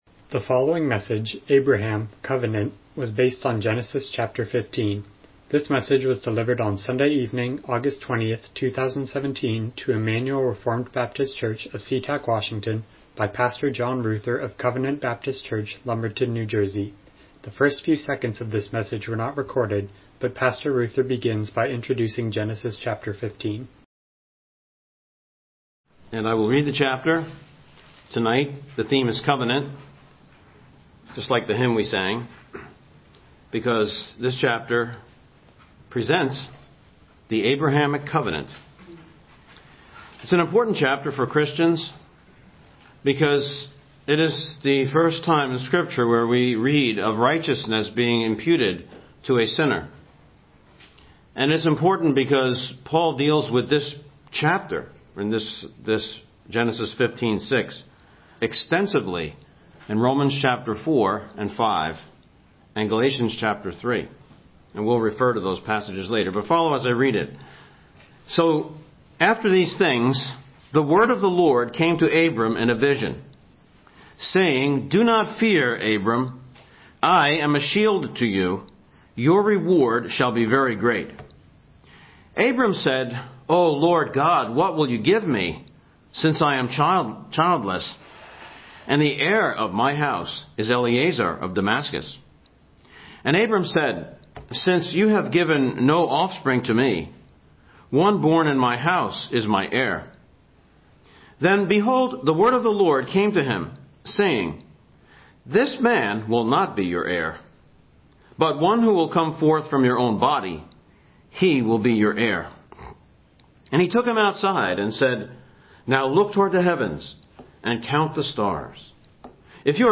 Miscellaneous Passage: Genesis 15:1-21 Service Type: Evening Worship « Abraham